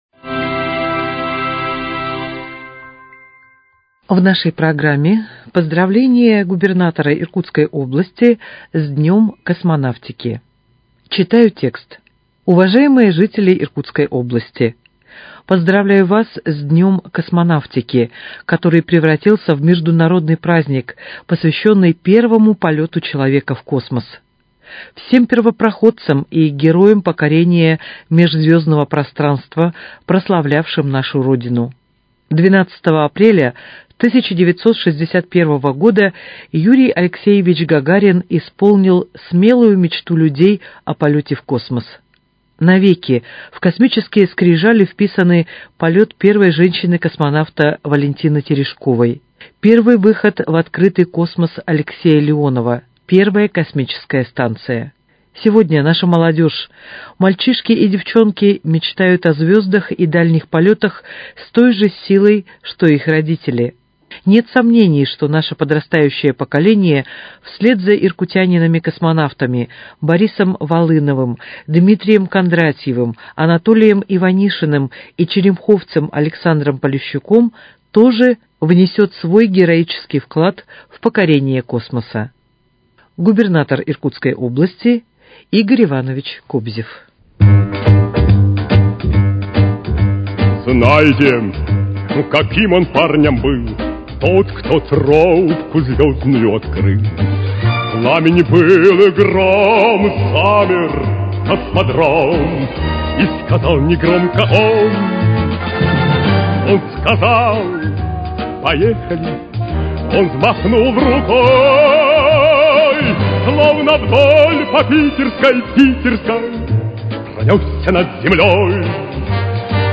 Поздравление Губернатора Иркутской области Игоря Ивановича Кобзева с Днем космонавтики